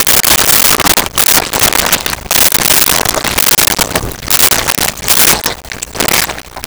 Footsteps Mud Liquid
Footsteps Mud Liquid.wav